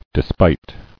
[de·spite]